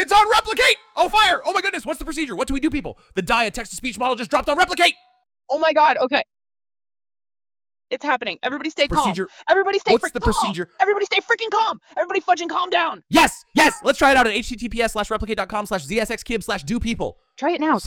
Dialogue SynthesisVoice CloningText-to-SpeechNon-Verbal Audio Generation
Generates realistic dialogue audio from text with speaker tags and non-verbal cues, optionally cloning voices from an audio prompt.
• Realistic multi-speaker dialogue generation
• Non-verbal sound production (e.g., laughs, whispers)